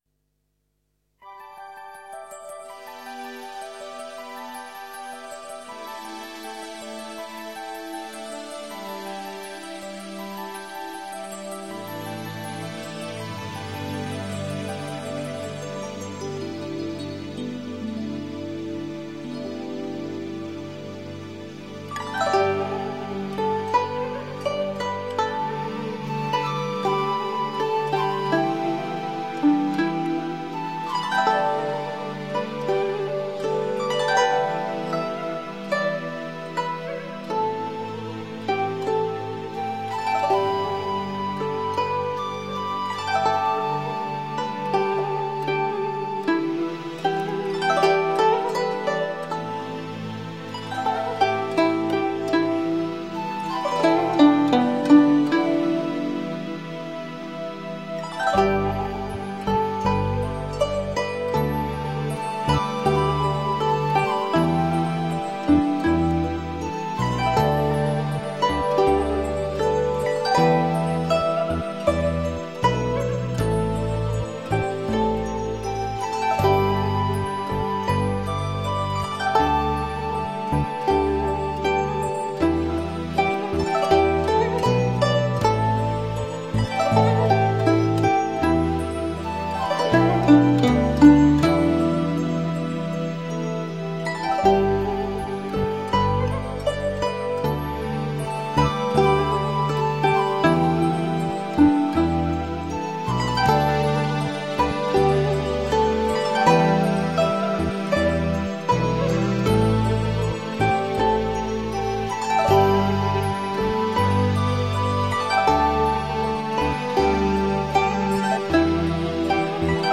佛音 诵经 佛教音乐 返回列表 上一篇： 水在瓶(菩薩偈